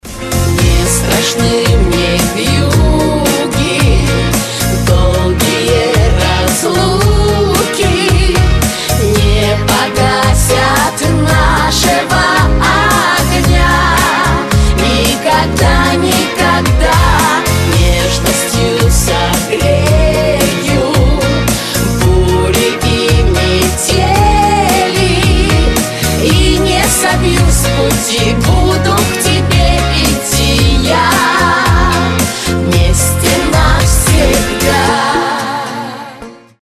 • Качество: 256, Stereo
поп
вокал